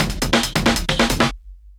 drums04.wav